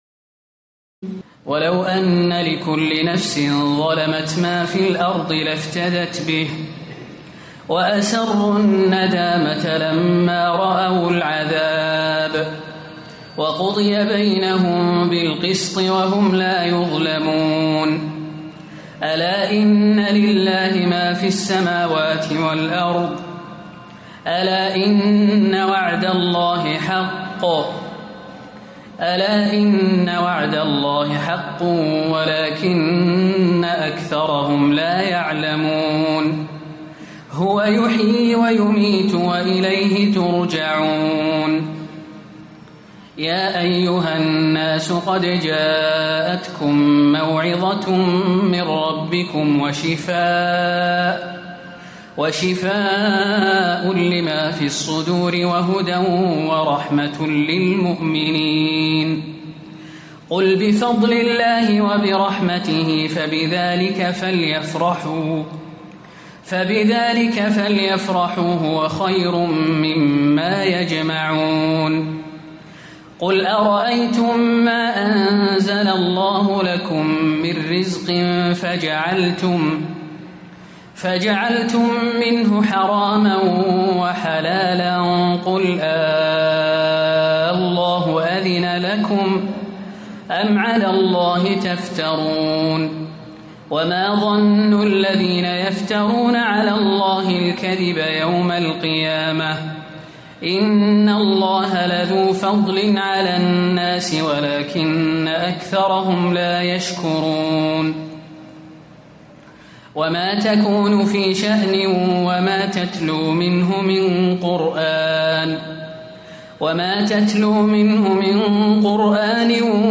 تراويح الليلة الحادية عشر رمضان 1437هـ من سورة يونس (54-109) و هود (1-35) Taraweeh 11 st night Ramadan 1437H from Surah Yunus and Hud > تراويح الحرم النبوي عام 1437 🕌 > التراويح - تلاوات الحرمين